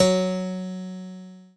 PIANO5-06.wav